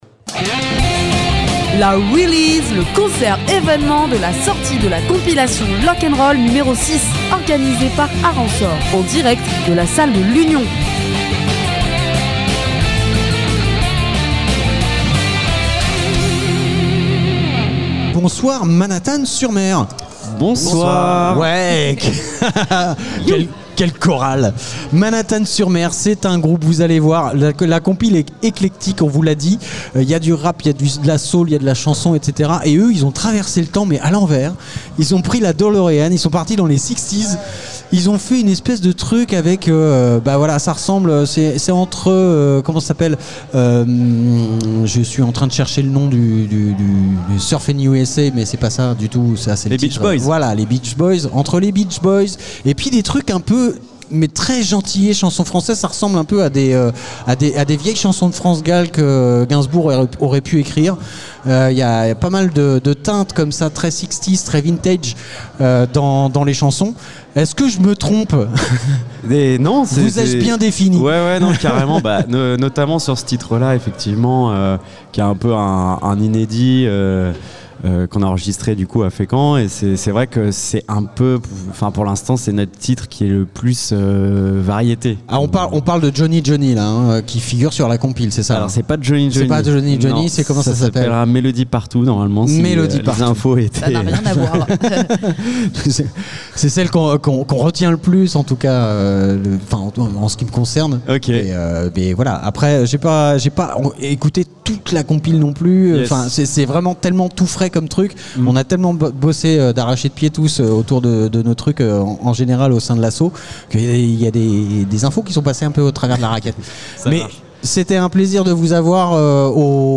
organisait un concert à la salle de l'union de Fécamp
Interview